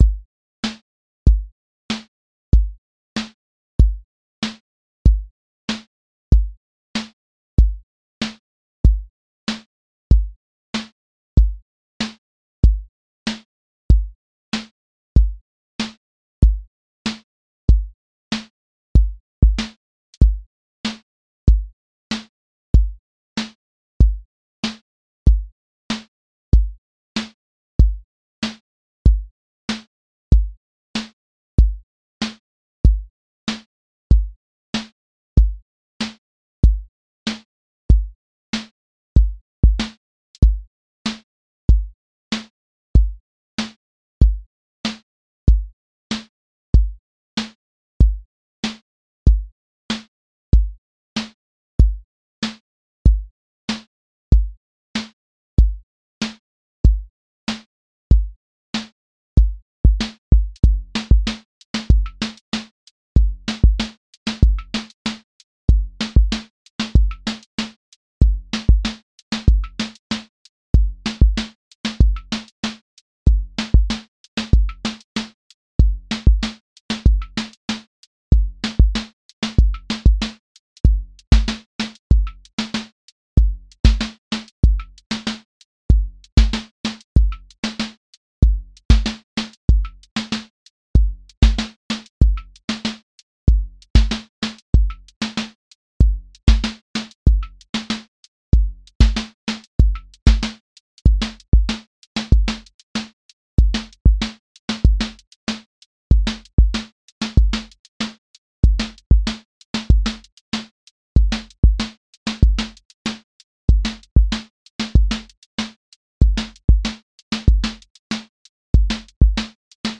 QA Listening Test boom-bap Template: boom_bap_drums_a
• voice_kick_808
• voice_hat_rimshot
A long-form boom bap song with clear loop, verse, bridge, and return contrast. Keep the sub grounded and make the counter rhythm audible